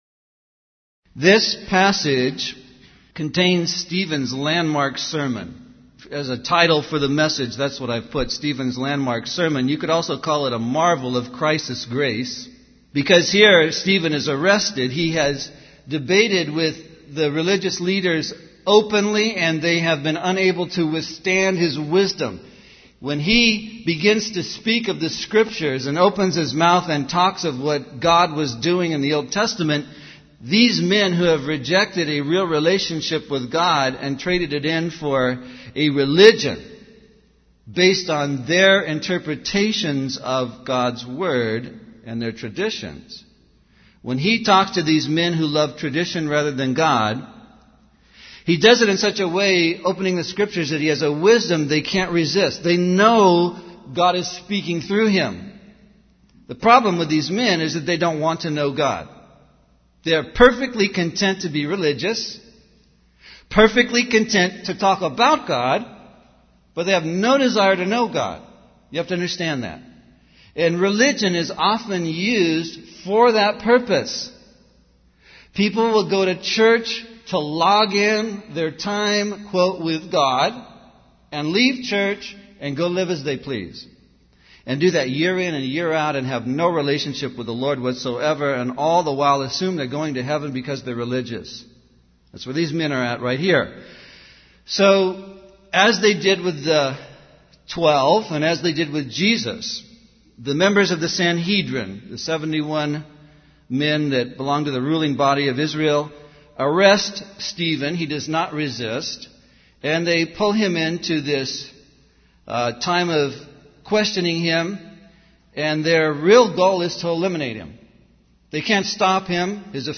In this sermon, the preacher discusses the story of Moses and how God trained him for forty years in the desert before calling him to be the deliverer of the Israelites. The preacher emphasizes the power of the Holy Spirit and encourages the audience to seek that power in their own lives. He then shifts to the story of Stephen, the first martyr for preaching the name of Christ, and highlights the excellence of Stephen's sermon despite his execution.